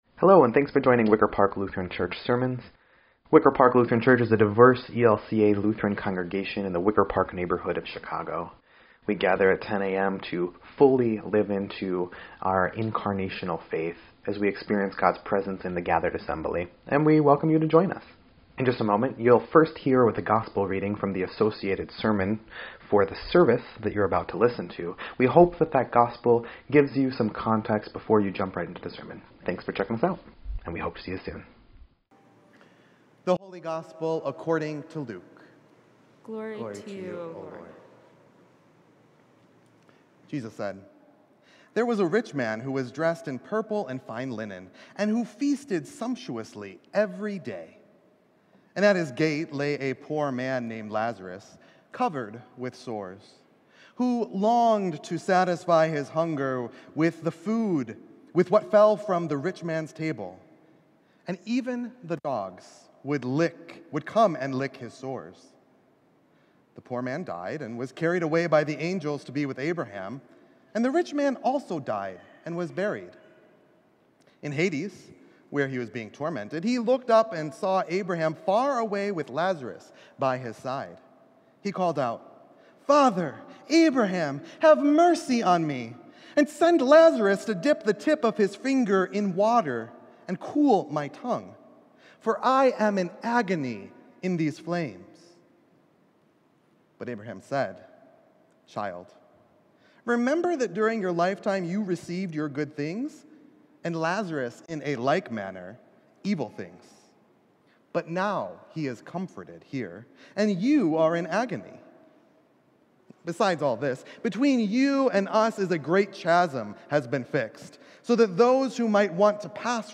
9.25.22-Sermon_EDIT.mp3